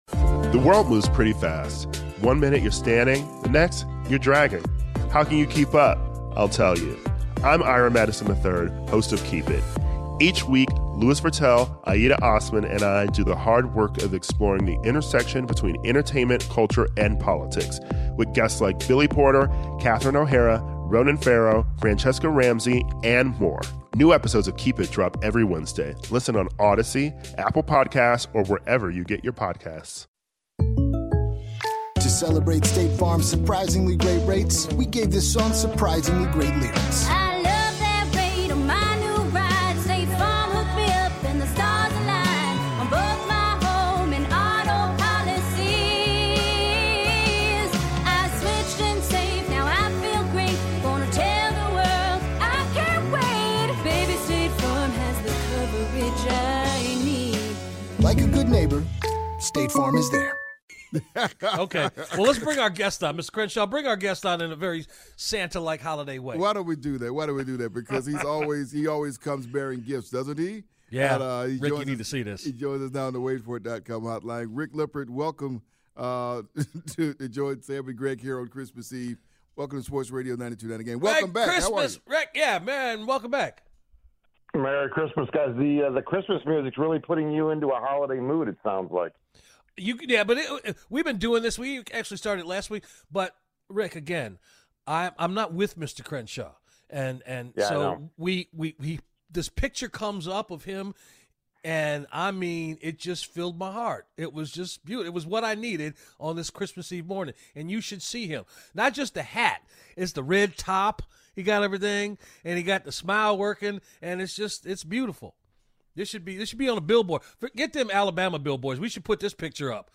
appearance on 92.9 The Game in Atlanta on Christmas Eve